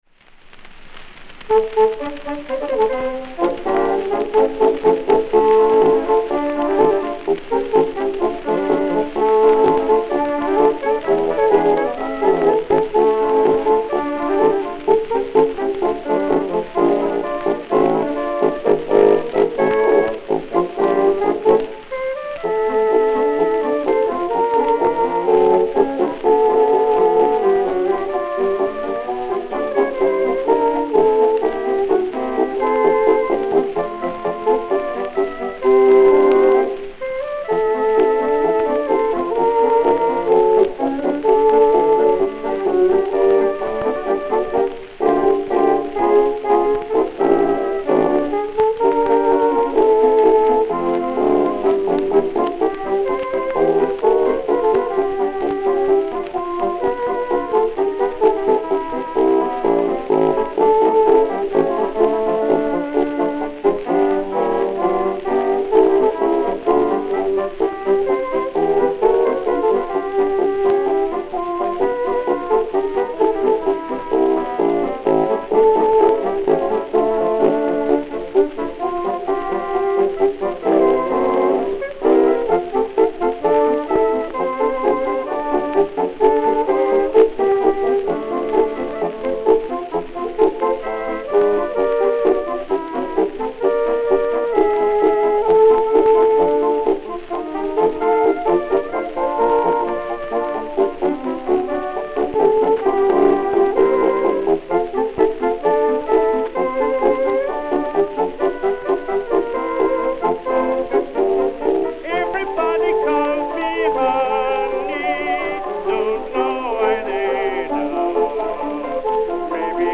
Incidental Chorus